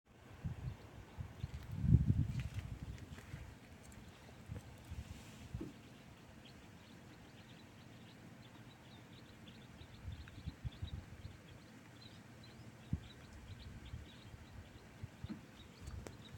Krustknābis Loxia sp., Loxia sp.
Administratīvā teritorijaRojas novads
Skaits22